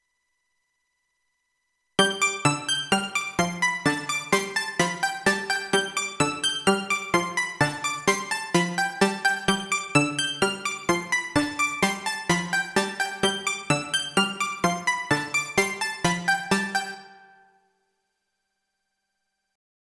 128 BPM
Merry Celtic jig
G mixolydian lute pluck w/ 65 swing, walking lute bass
tavern-jig.mp3